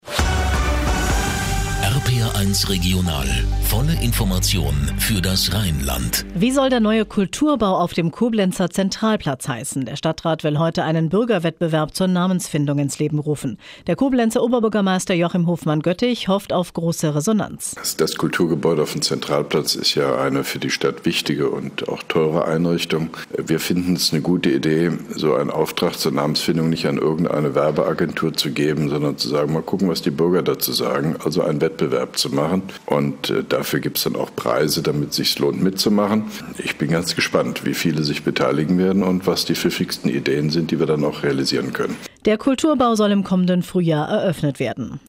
Ausschnitt: RPR1 Regional, Informationen für das Rheinland, Studio Koblenz, 9.30 Uhr,  23.03.2012
Mit einem Kurzinterview von OB Hofmann-Göttig